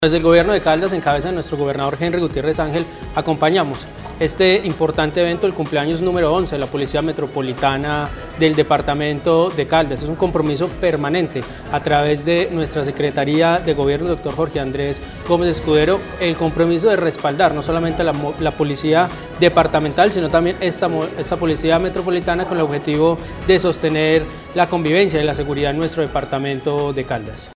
Ronald Fabián Bonilla Ricardo, Gobernador (e) de Caldas